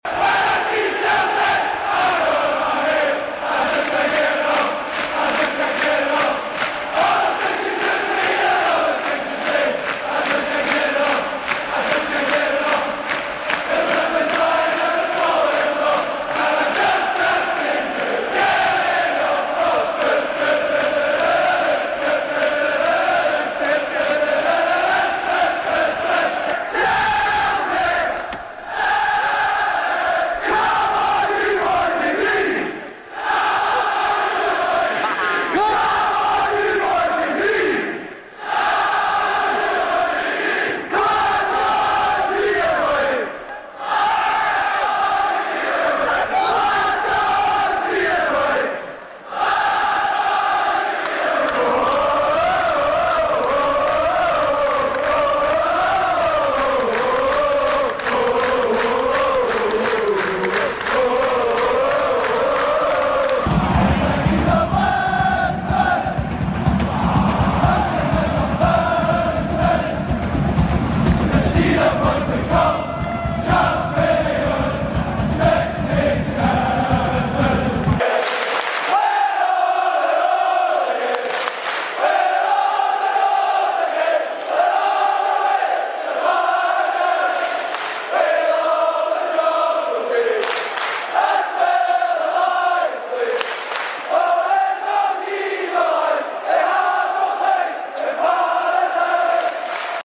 Celtic Best Chants Part sound effects free download